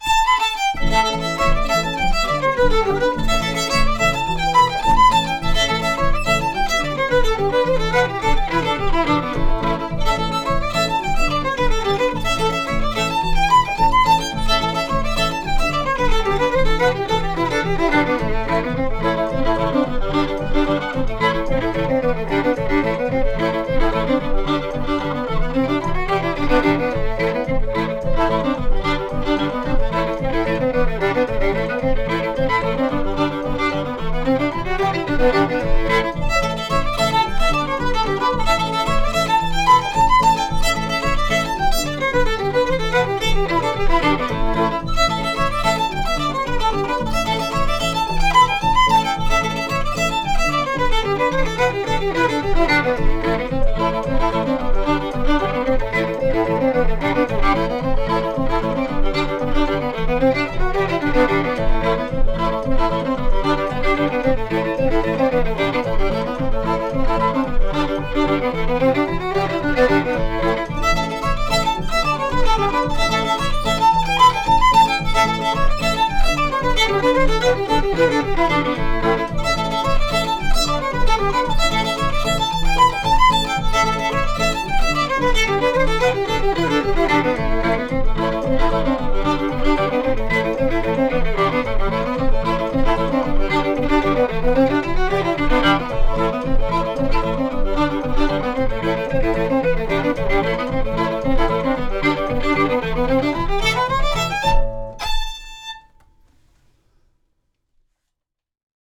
pno